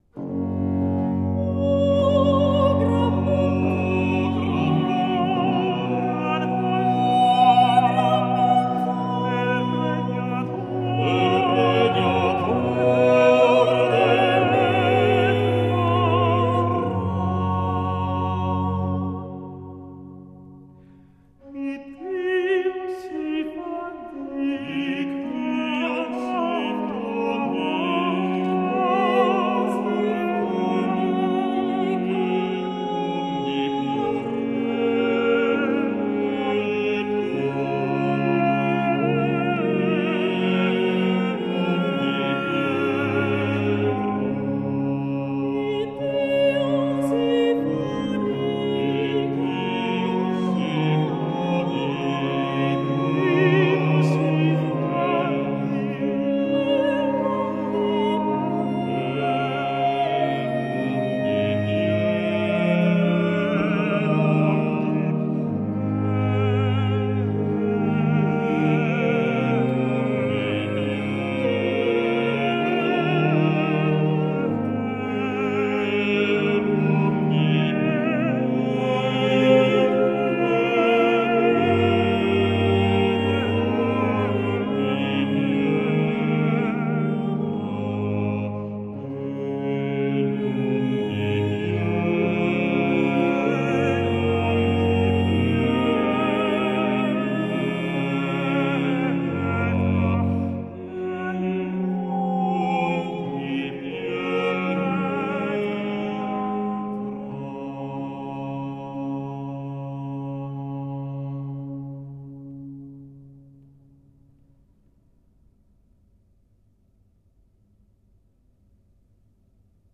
Trio 'O gran bontà'.mp3 — Laurea Triennale in Scienze e tecnologie della comunicazione